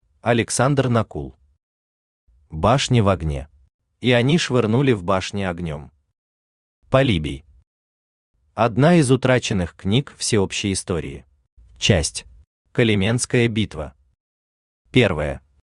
Аудиокнига Башни в огне | Библиотека аудиокниг
Aудиокнига Башни в огне Автор Александр Накул Читает аудиокнигу Авточтец ЛитРес.